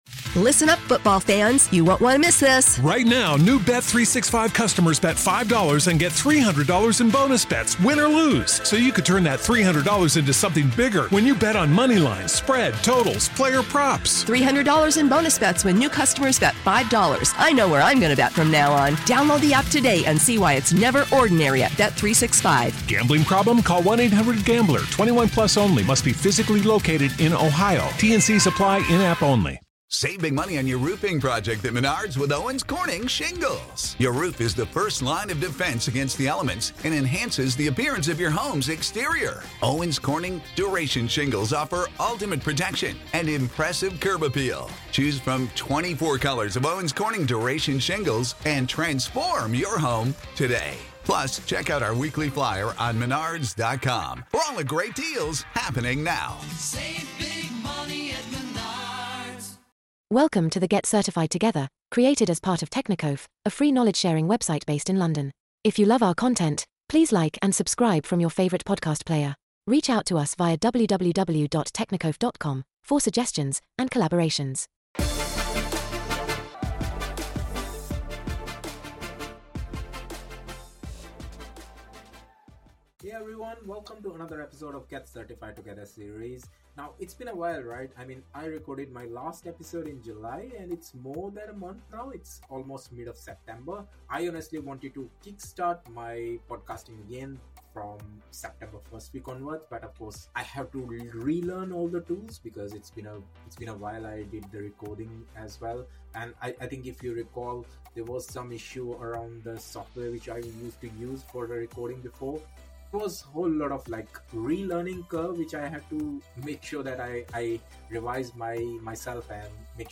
Elissa Slotkin on the Democratic Party 30:40 Play Pause 5d ago 30:40 Play Pause Play later Play later Lists Like Liked 30:40 How will MAGA Republicans find their way out of the government shutdown? Jessica Tarlov is joined by Michigan Senator Elissa Slotkin to talk about some possibilities, and some fissures in Trumpworld that might signal the standoff’s end.